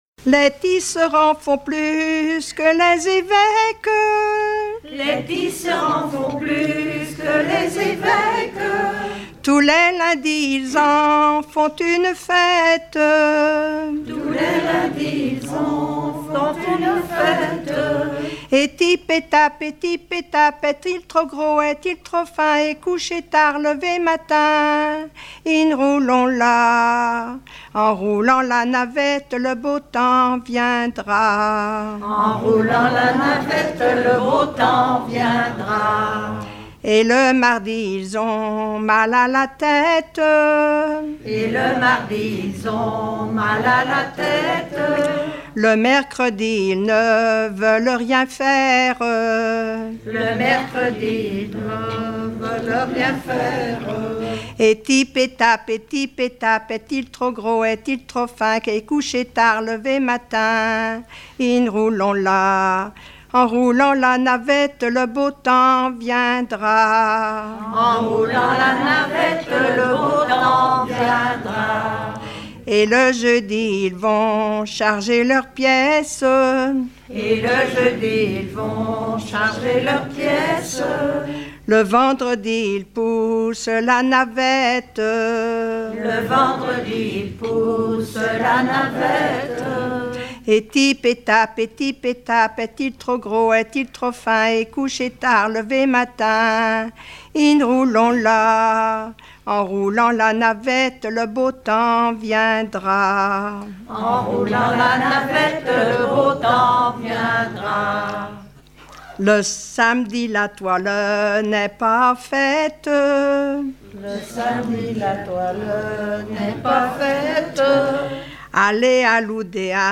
Répertoire de chansons populaires et traditionnelles
Pièce musicale inédite